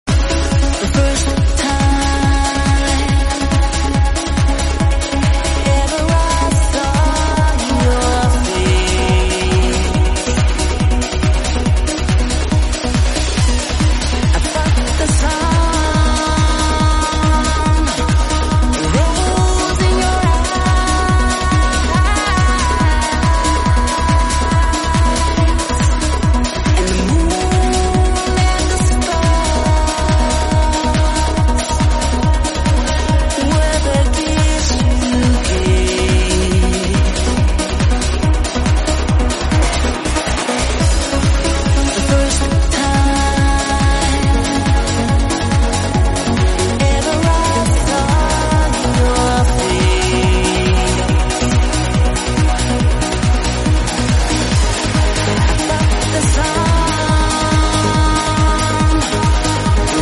Trance Sets | 138 BPM